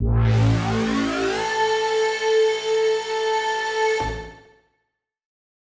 Index of /musicradar/future-rave-samples/Poly Chord Hits/Straight
FR_ProfMash[hit]-A.wav